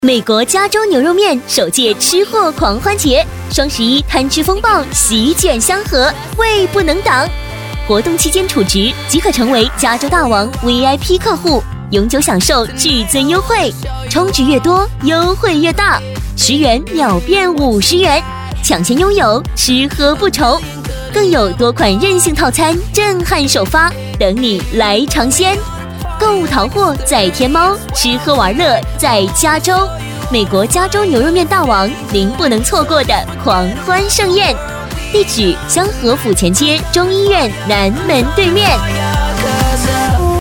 【促销】美国加州牛肉面美食女37-甜美
【促销】美国加州牛肉面美食女37-甜美.mp3